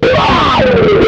MANIC SLIDE3.wav